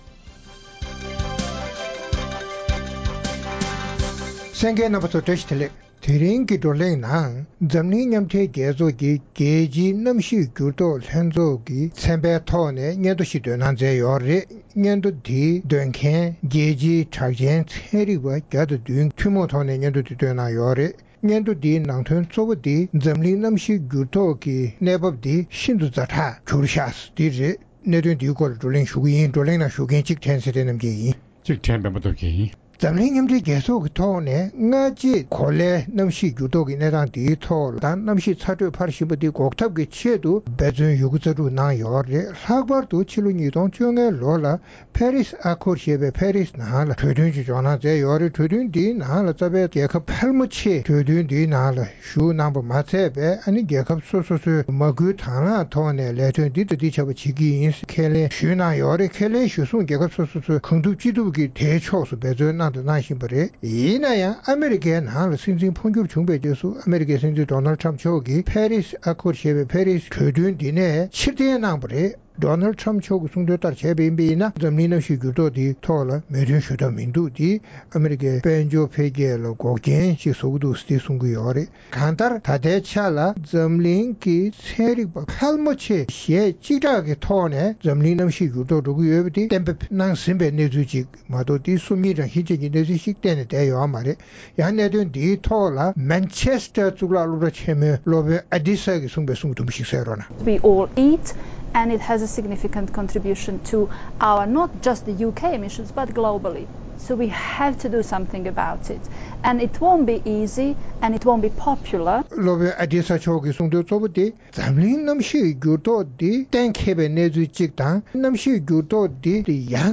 ༄༅།།ཐེངས་འདིའི་རྩོམ་སྒྲིག་པའི་གླེང་སྟེགས་ཞེས་པའི་ལེ་ཚན་ནང་། འཛམ་གླིང་མཉམ་སྦྲེལ་རྒྱལ་ཚོགས་ཀྱི་རྒྱལ་སྤྱིའི་གནམ་གཤིས་འགྱུར་ལྡོག་ལྷན་ཚོགས་ཀྱི་ཚན་རིག་པ་༡༠༧་གྱིས་གནམ་གཤིས་ཚ་དྲོད་འཕར་བཞིན་པའི་ཛ་དྲག་གནས་སྟངས་སྐོར་སྙན་ཐོ་བཏོན་པར་རྩོམ་སྒྲིག་འགན་འཛིན་རྣམ་པས་བགྲོ་གླེང་གནང་བ་ཞིག་གསན་རོགས་གནང་།།